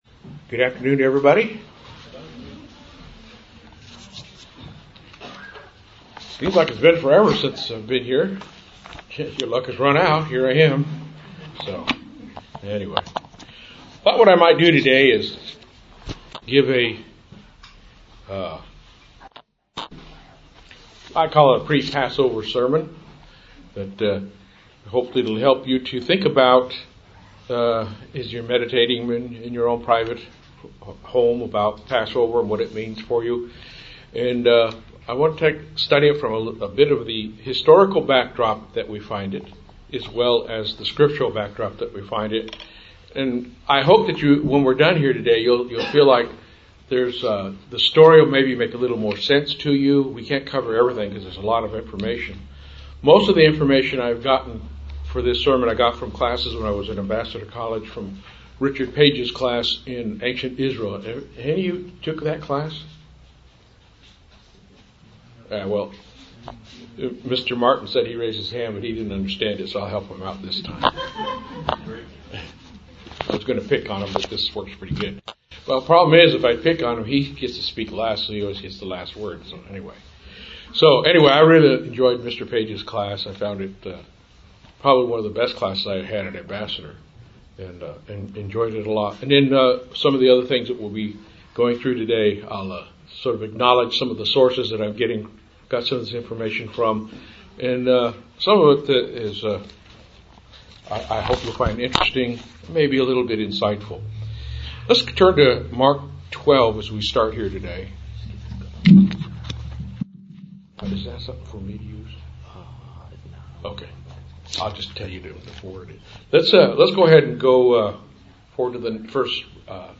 Will cover some little known detail behind the Crucifixion of Jesus Christ. This is a pre-Passover message.
Given in Fort Worth, TX